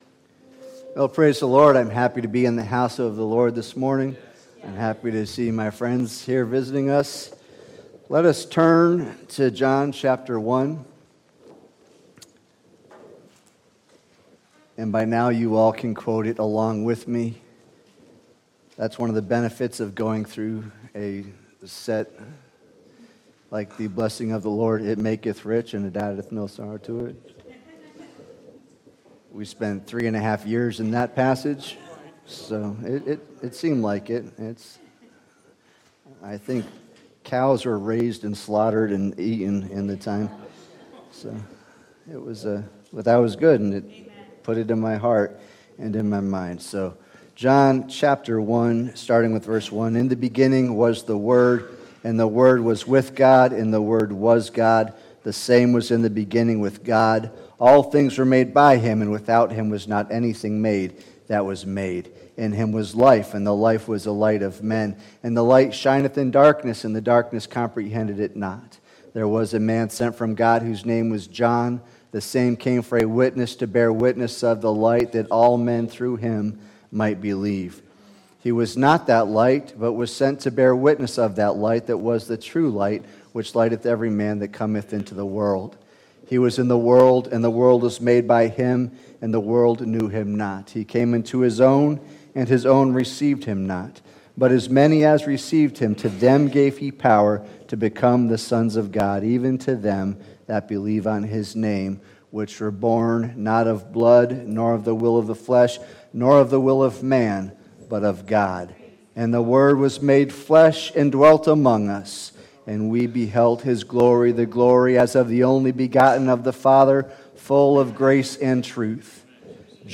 Podcast of preaching and teaching at the Apostolic Church of Enfield, CT